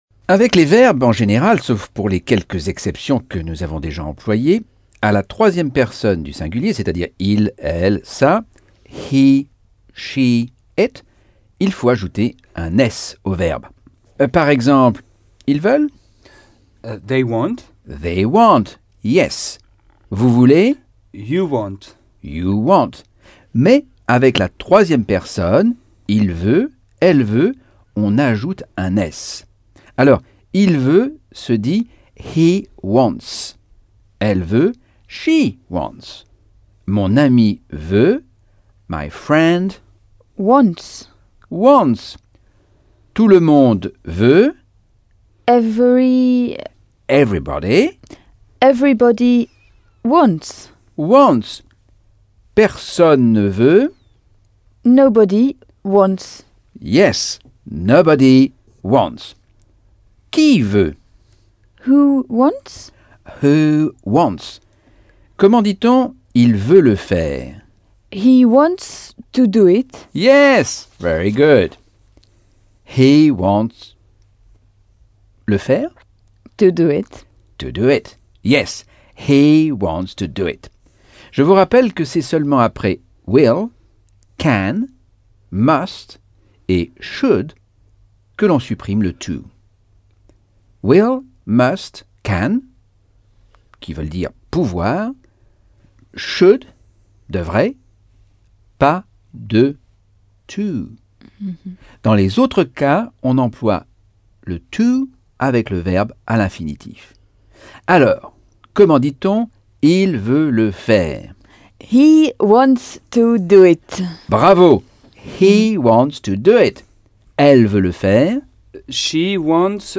Leçon 4 - Cours audio Anglais par Michel Thomas